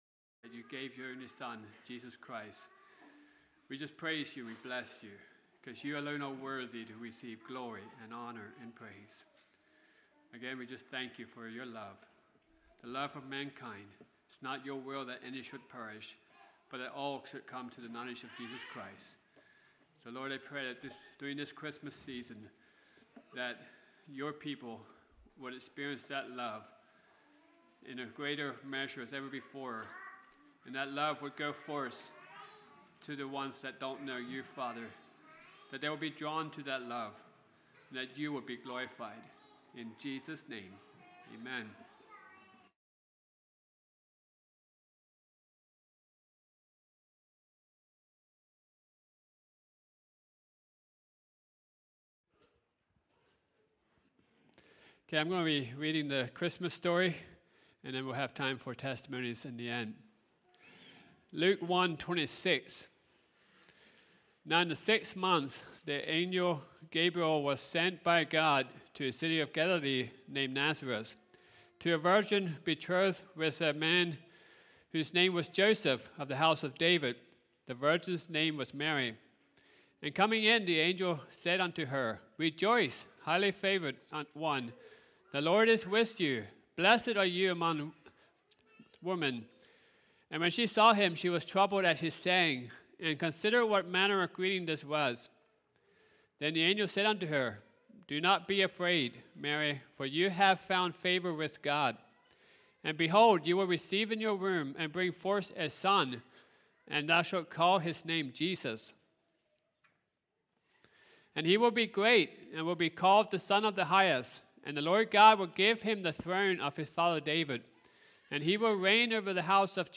The reading of the Christmas story
Service Type: Sunday Morning